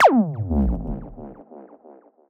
Synth Fx Stab 05.wav